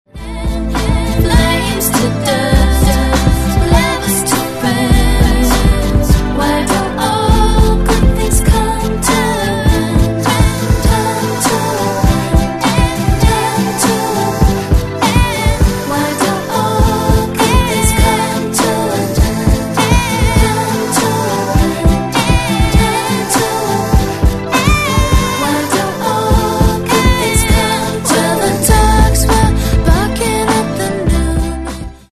R'n'B